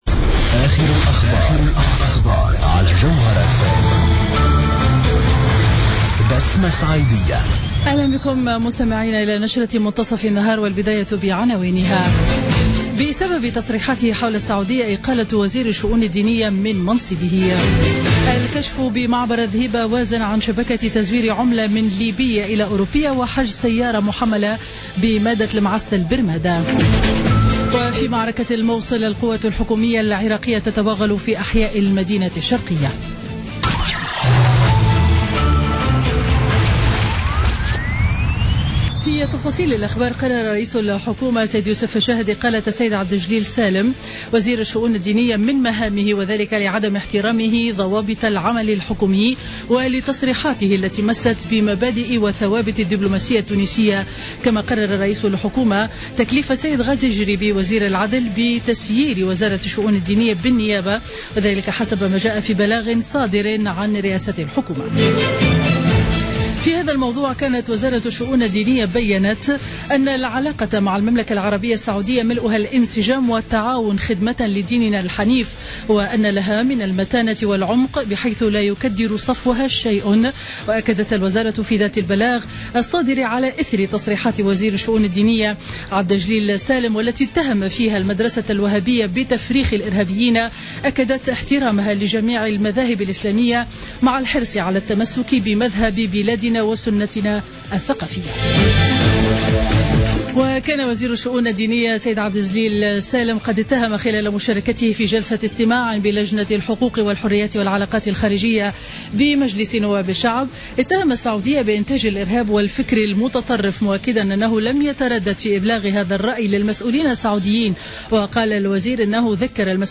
نشرة أخبار منتصف النهار ليوم الجمعة 4 نوفمبر 2016